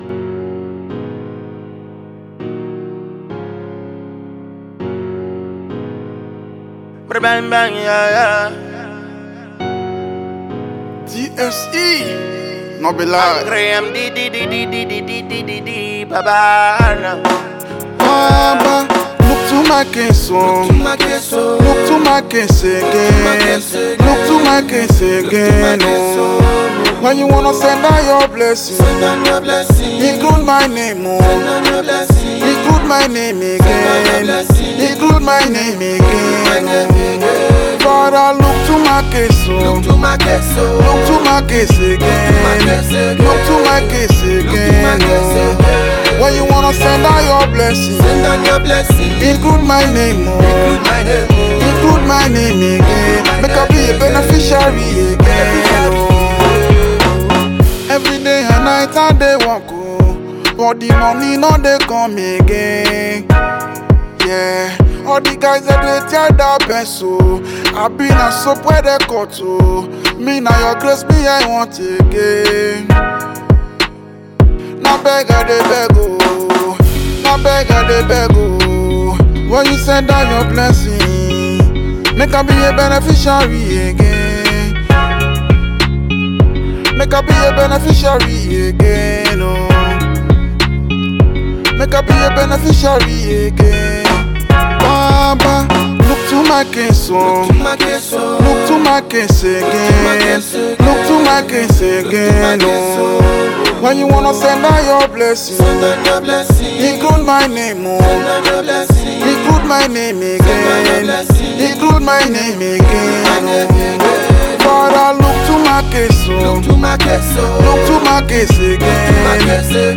prayer tune